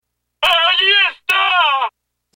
Chaque bouchon a une voix enregistrée qui lui est associée, cliquez sur le nom du bouchon pour l'écouter.